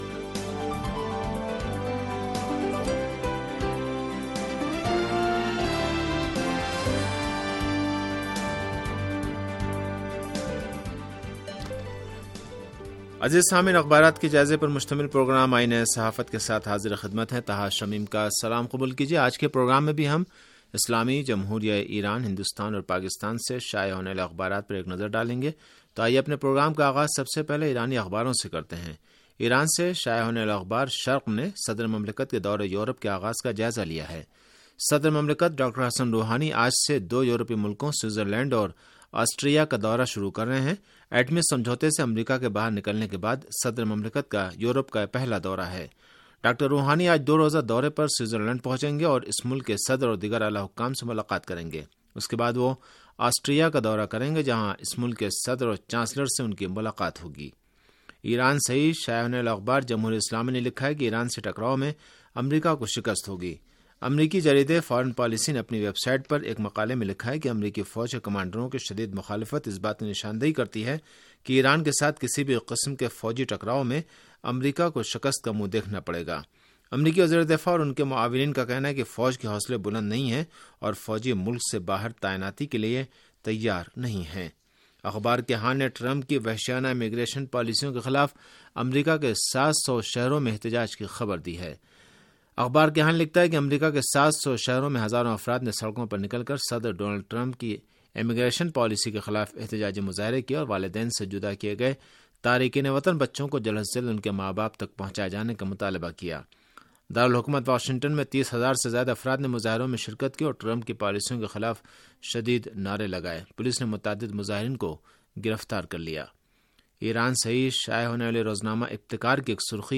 ریڈیو تہران کا اخبارات کے جائزے پر مبنی پروگرام